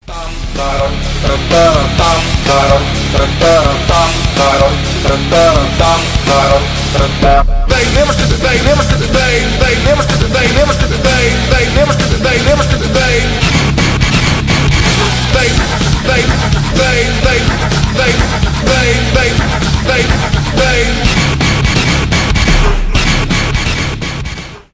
рок
alternative metal , mashup